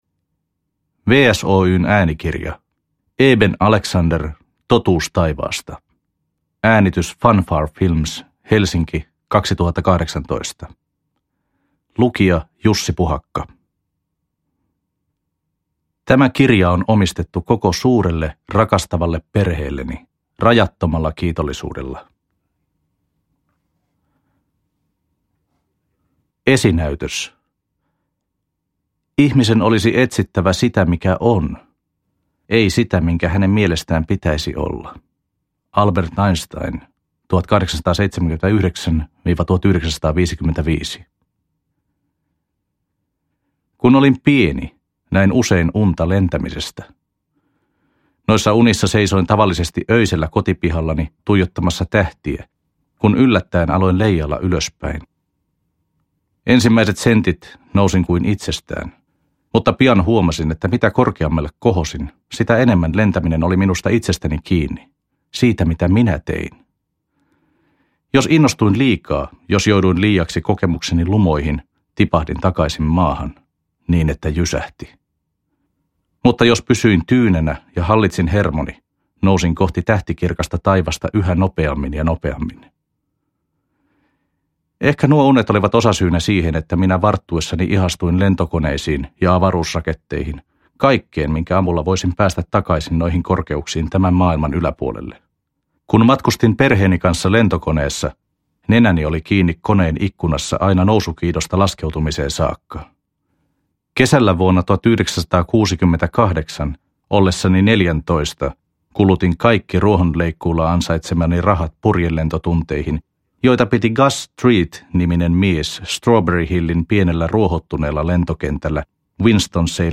Totuus taivaasta – Ljudbok